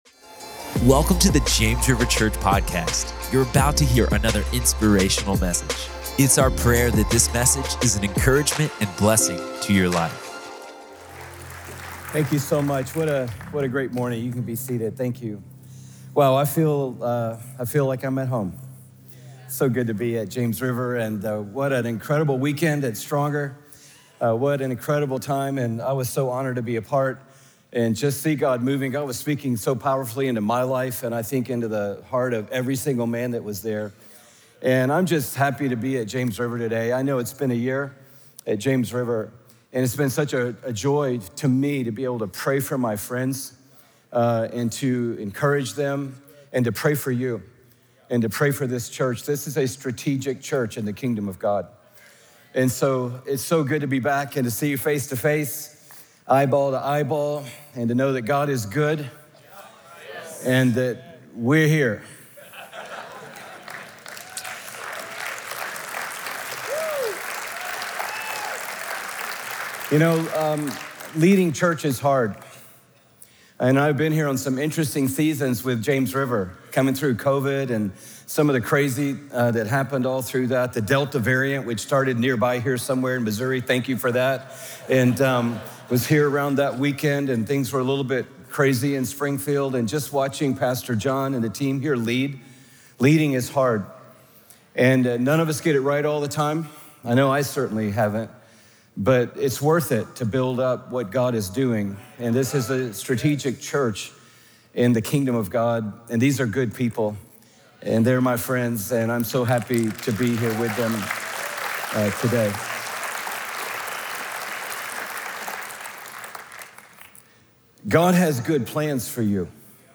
In this message, special guest Pastor Louie Giglio unpacks the truth of Jeremiah 29:11—reminding us that even in seasons of delay or difficulty, God is working good plans for our lives. When we seek Him with our whole heart, we discover hope, restoration, and a future filled with purpose.